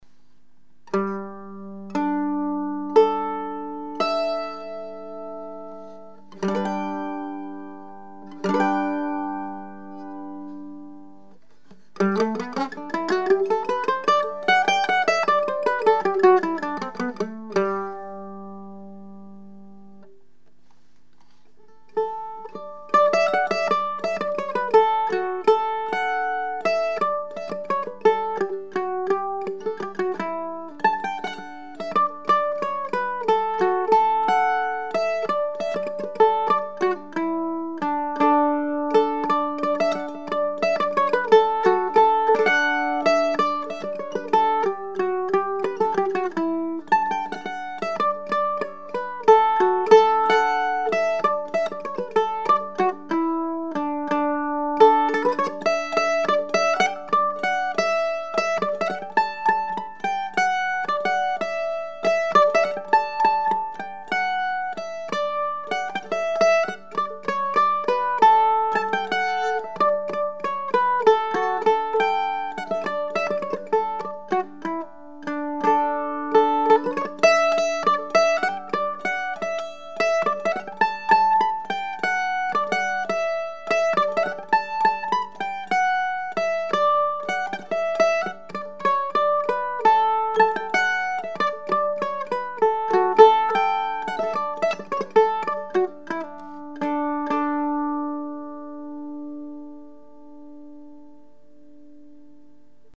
Classical Flattop Mandolin #341  $3200 (includes case)
The top is about 30% lighter and gives more volume and improved tone.
A clean, clear evenly balanced sound.